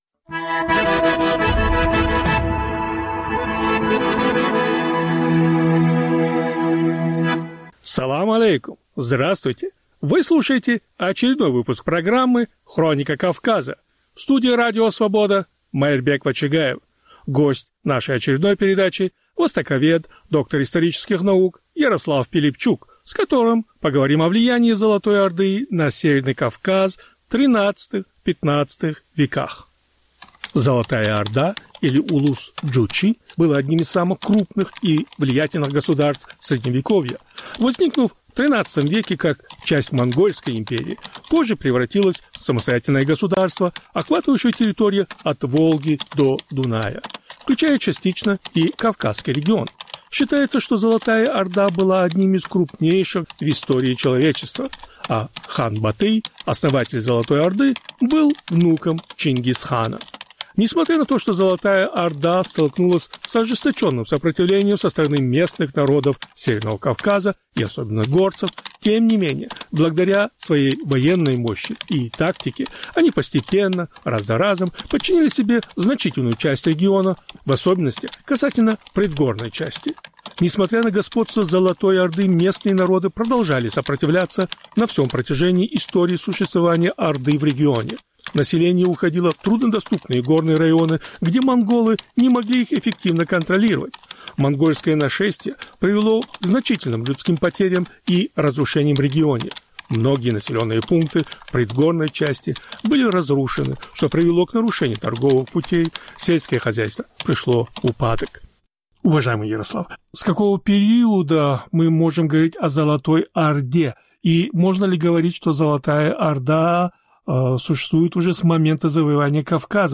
Повтор эфира от 26 января 2025 года.